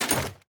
Equip_netherite2.ogg.mp3